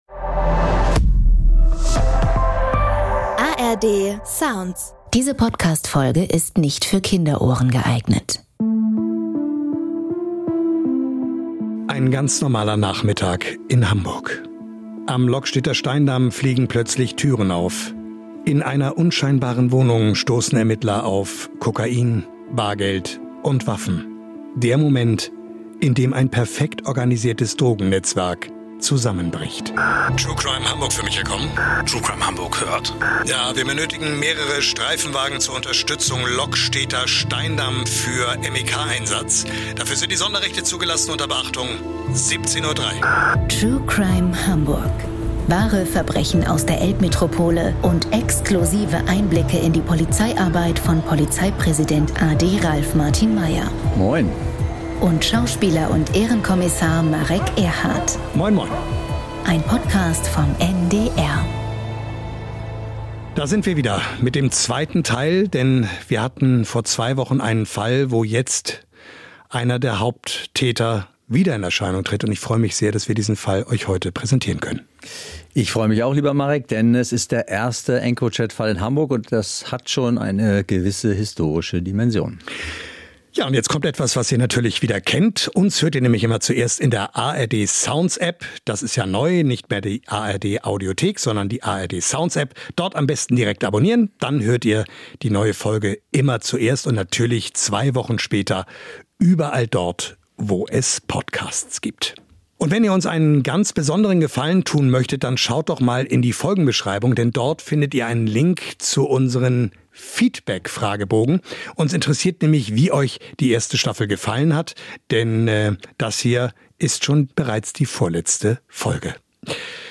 Im Gespräch mit Schauspieler Marek Erhardt schildert Hamburgs Polizeipräsident a.D. Ralf Martin Meyer, wie klassische Ermittlungsarbeit und moderne Datenanalyse zusammenwirkten und welche juristische Debatte die Verwertbarkeit der EncroChat-Daten vor Gericht auslöste.